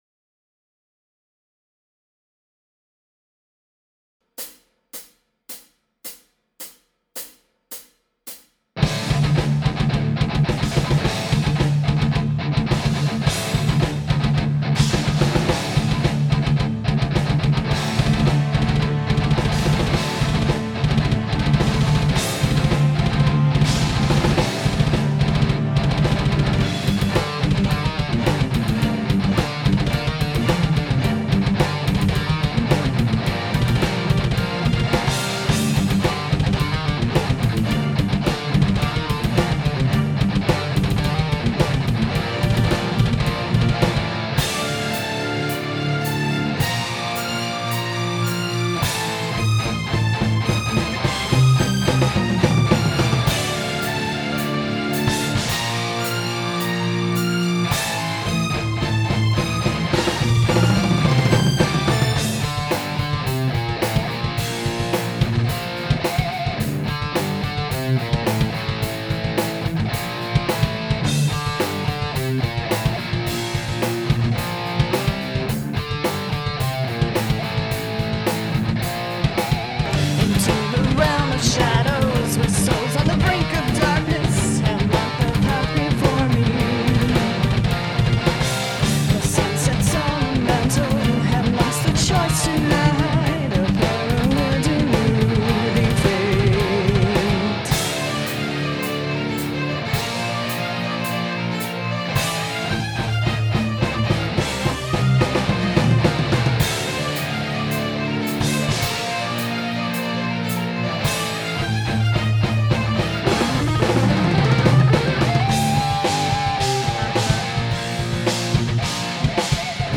Other bands had music done, but this is the first track recorded in house in the basement vault/studio of Metal Storm Towers.
Enjoy the extremely rough, un-mixed, un-mastered majesty of BitchTroll's "The Siren"
Again, it's just a demo, but it is so hot I'm typing with oven mitts!
The vocals sound like a total wanna-be.
It's actually not bad, aside from the vocals.